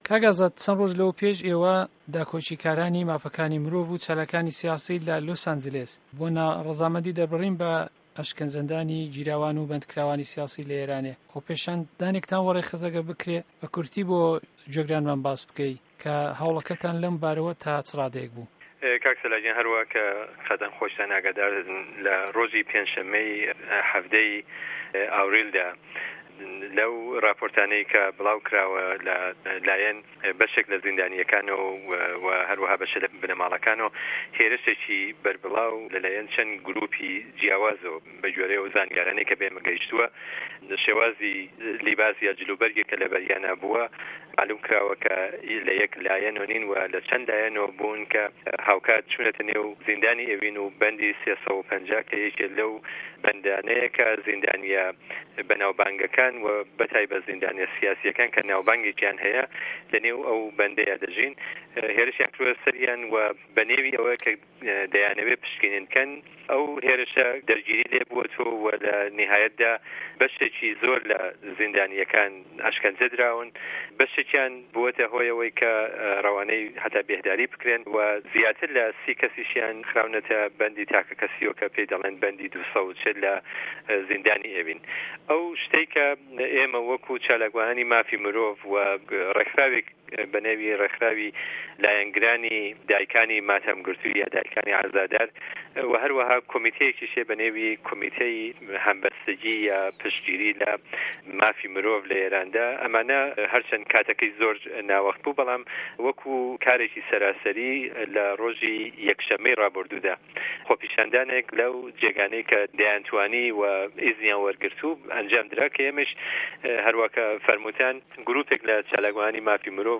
ووتووێژی